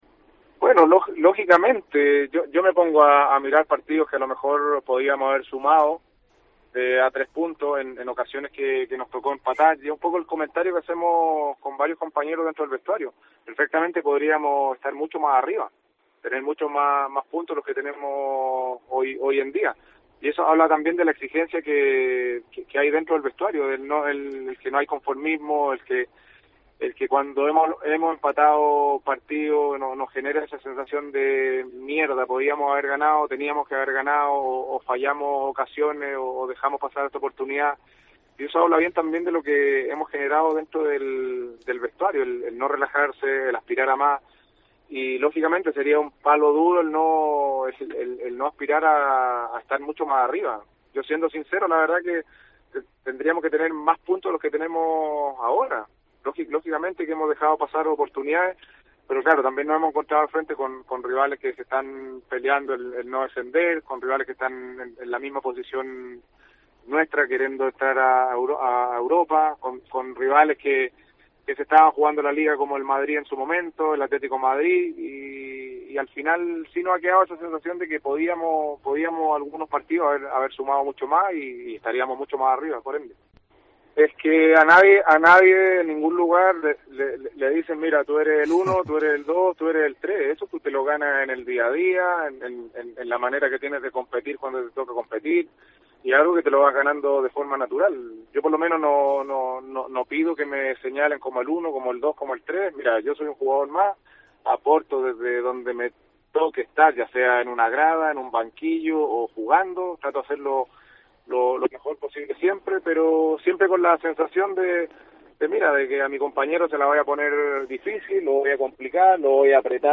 Aquí os dejamos una parte de su entrevista.